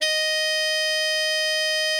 bari_sax_075.wav